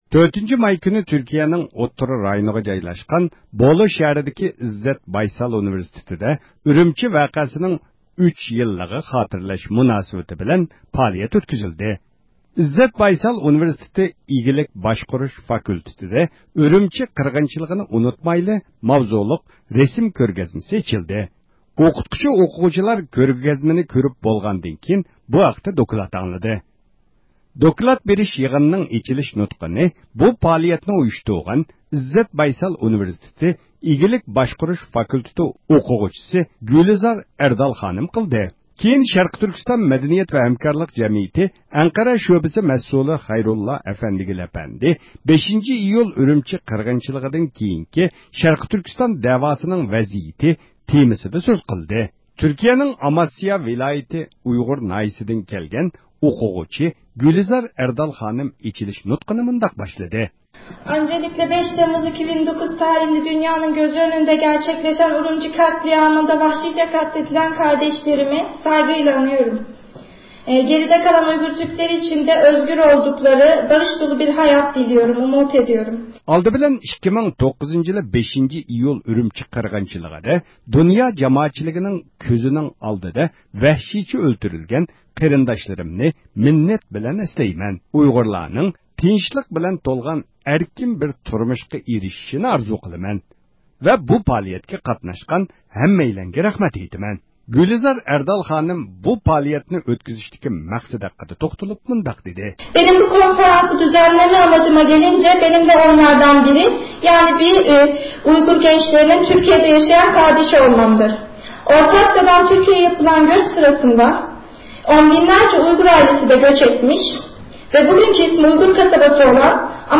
تۈركىيىدىكى ھەرقايسى ئۇنىۋېرسىتېتلاردا ئۈرۈمچى ۋەقەسى خاتىرىلىنىشكە باشلىدى. 4-ماي كۈنى تۈركىيىنىڭ ئوتتۇرا رايونىغا جايلاشقان بولۇ شەھىرىدىكى ئىززەت بايسال ئۇنىۋېرسىتېتىدا ئۈرۈمچى ۋەقەسىنىڭ 3 يىللىقىنى خاتىرىلەش مۇناسىۋىتى بىلەن پائالىيەت ئۆتكۈزۈلدى.
بىز يىغىن ئاخىرلاشقاندىن كېيىن ئوقۇغۇچىلارغا مىكروفونىمىزنى ئۇزاتتۇق.